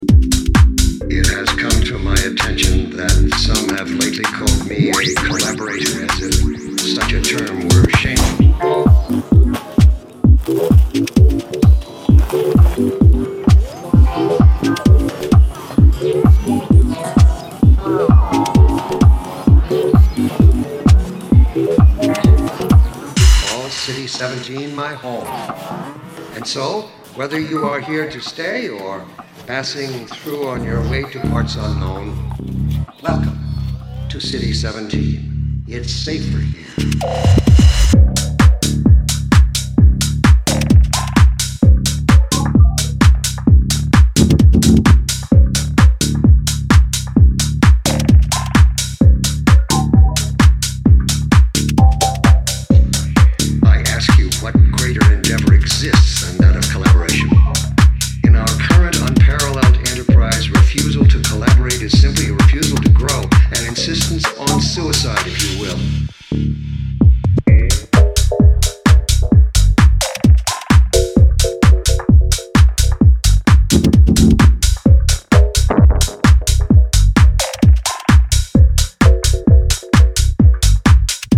a new various artists LP a bit more clubby than usual